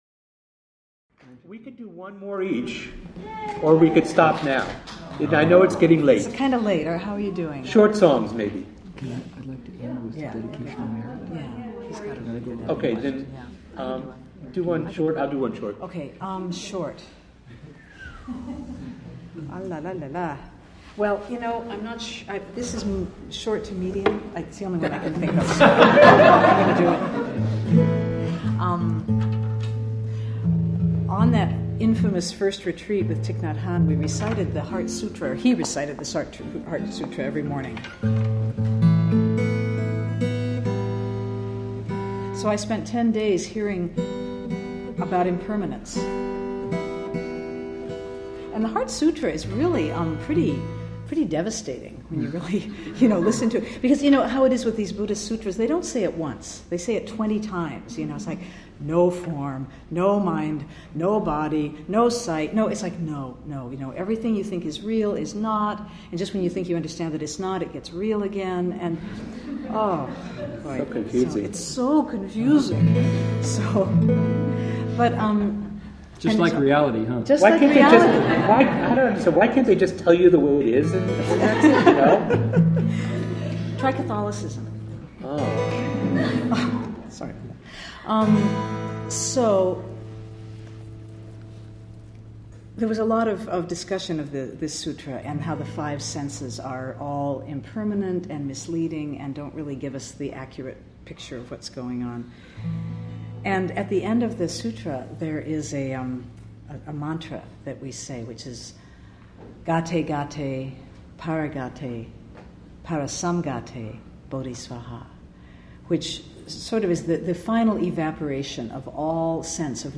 three Western practitioners of Buddhism and of music.
Music in the Dharma / Dharma in the Music / Live at IMC
Their folk-rooted acoustic music combines tradition and innovation much as our practice here in California does the same.